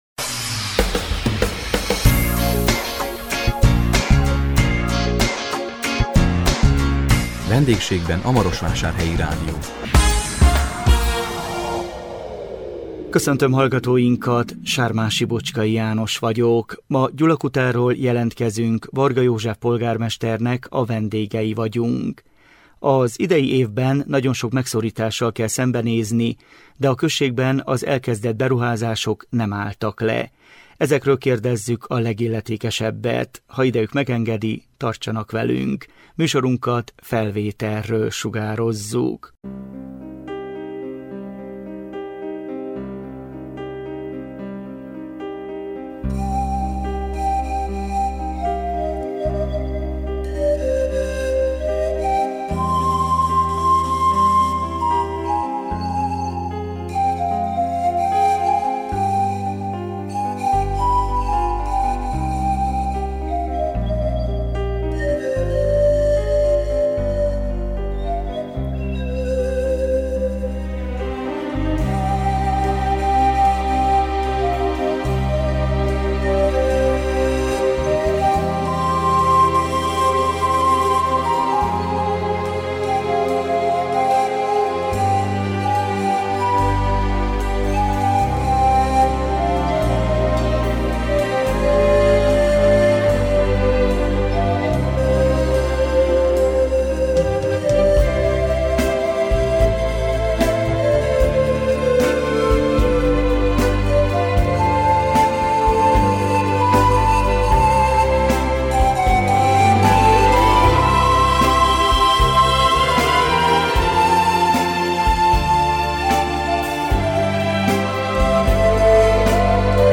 A 2025 november 27-én közvetített VENDÉGSÉGBEN A MAROSVÁSÁRHELYI RÁDIÓ című műsorunkkal Gyulakutáról jelentkeztünk, Varga József polgármesternek a vendégei voltunk. Az idei évben nagyon sok megszorítással kell szembenézni, de a községben az elkezdett beruházások nem álltak le.